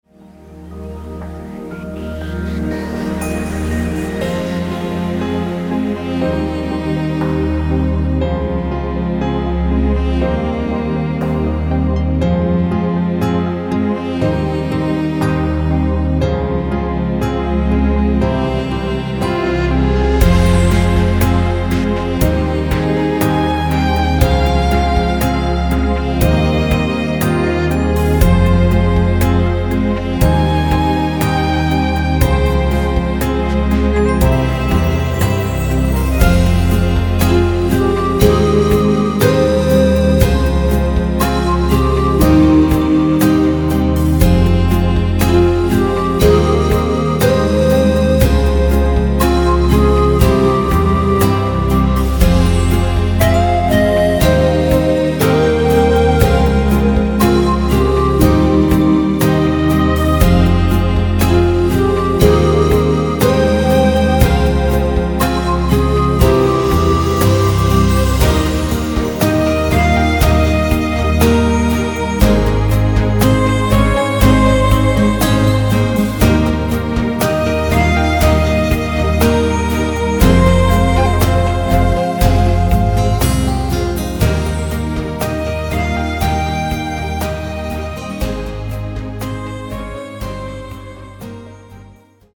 Særlige klangfulde sammensætninger.